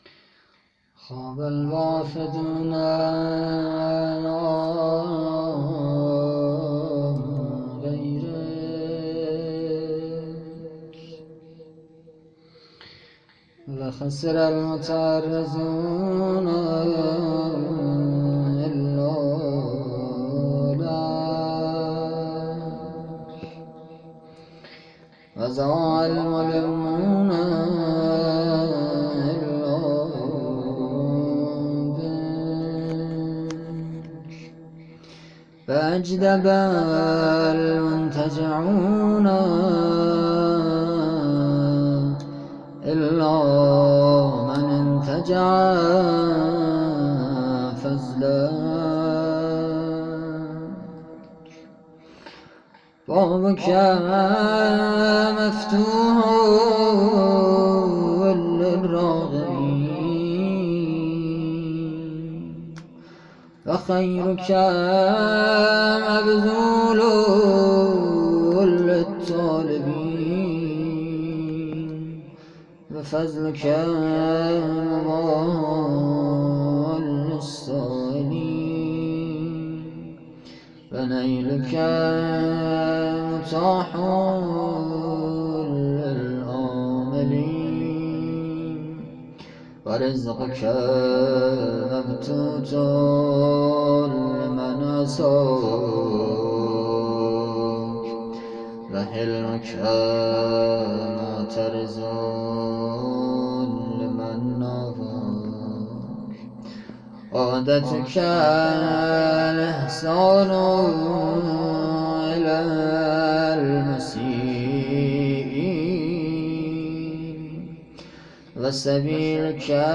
دعا